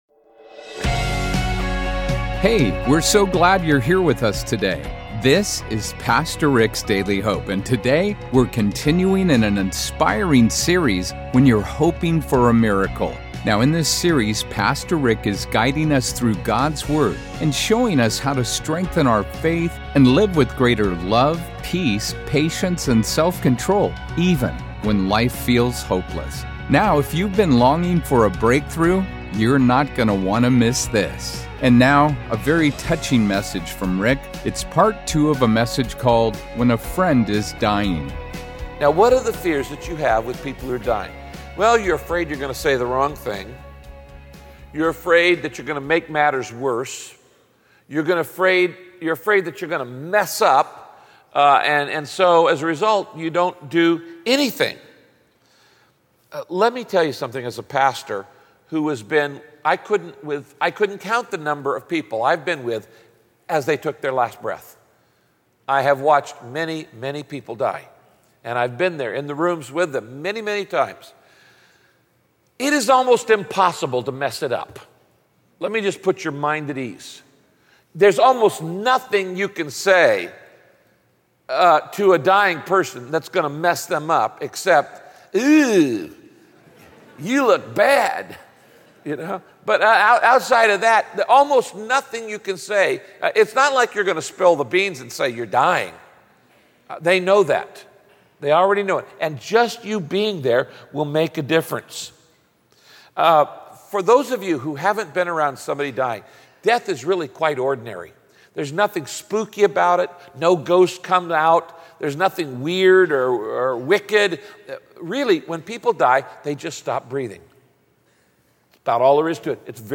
In this broadcast, Pastor Rick teaches how to show compassion to a dying friend by offering kindness, patience, and help in small, practical ways.